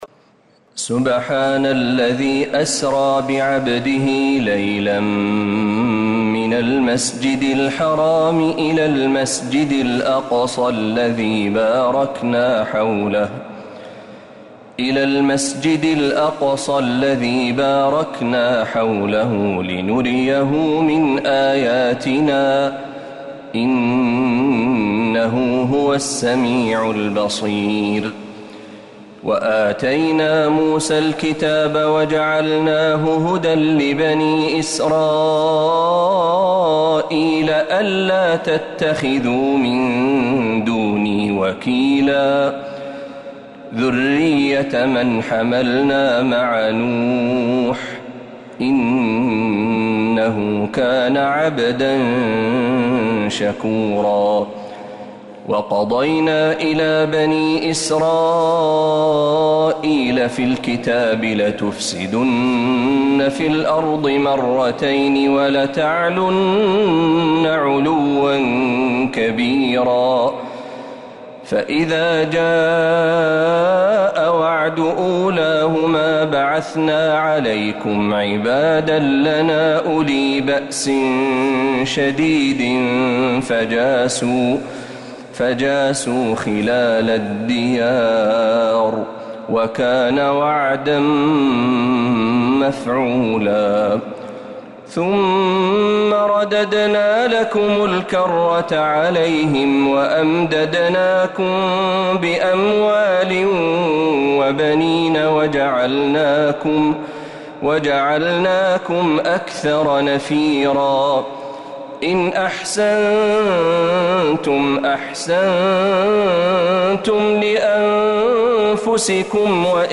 سورة الإسراء كاملة من الحرم النبوي